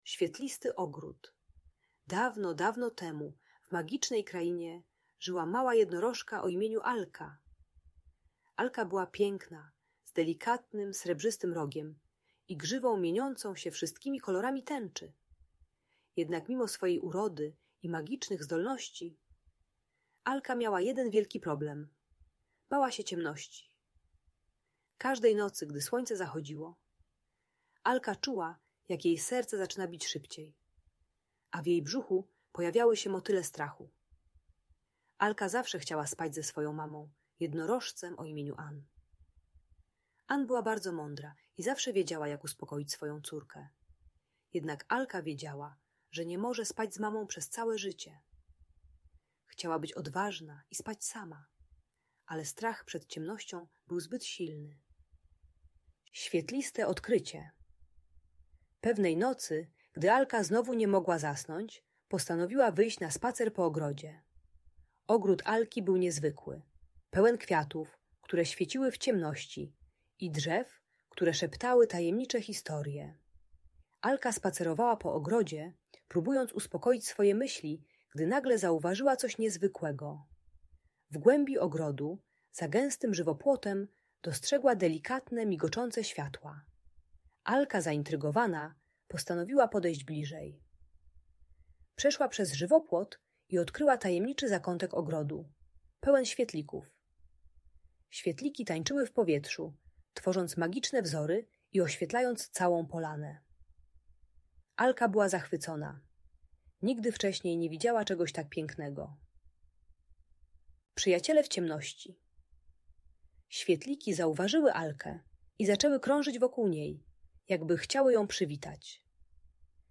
Świetlisty Ogród - Usypianie | Audiobajka